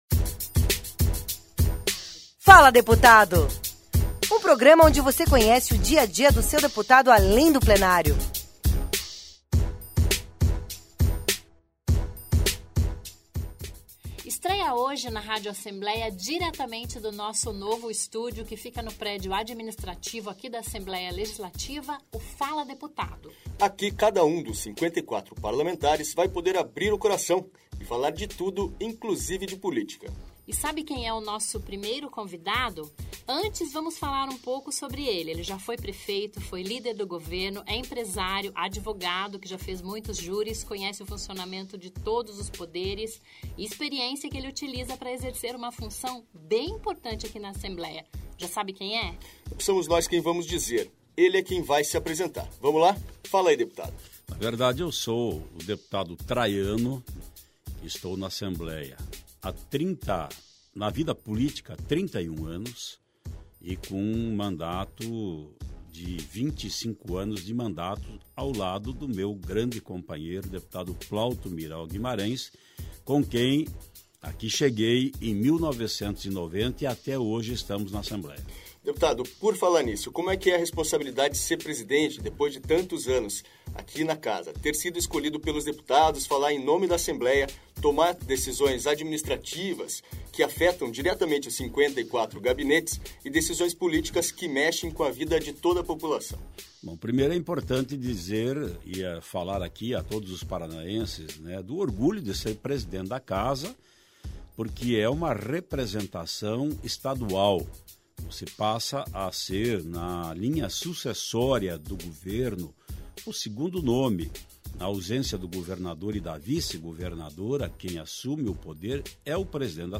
Assembleia Legislativa do Paraná | ALEP | Notícias > "Fala Deputado" estreia com uma entrevista reveladora do presidente Ademar Traiano.